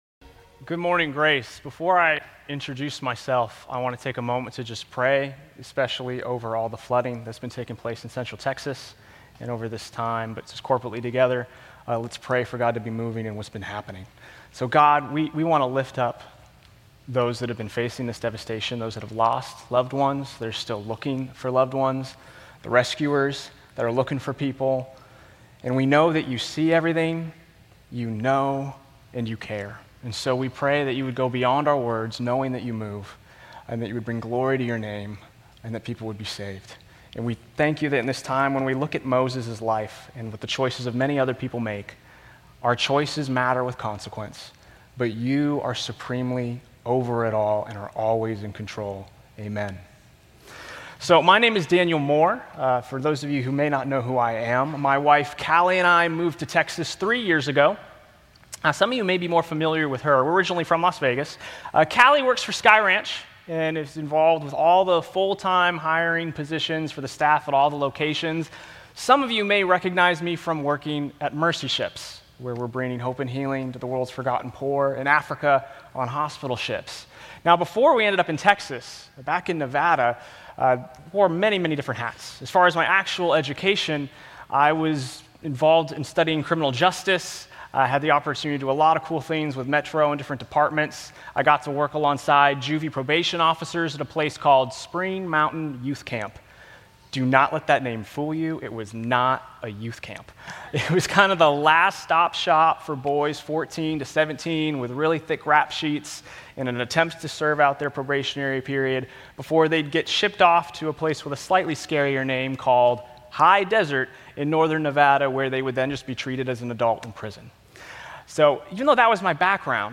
Grace Community Church Lindale Campus Sermons 7_6 Lindale Campus Jul 06 2025 | 00:24:41 1x 00:00 / 00:24:41 Subscribe Share RSS Feed Share Link Embed